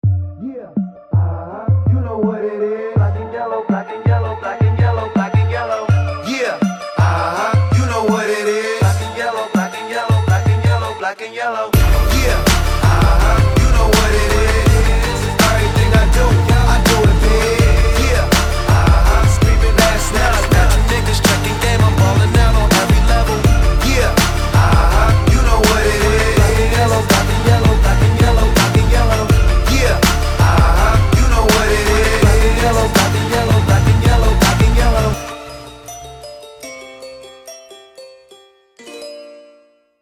GK_anthem.mp3